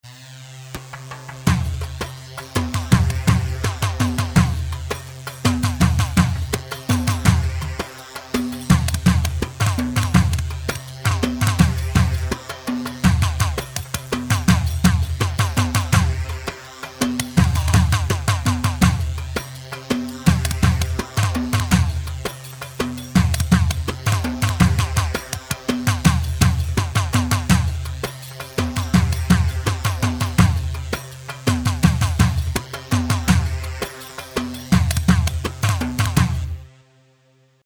Kashaba 4/4 166 خشابة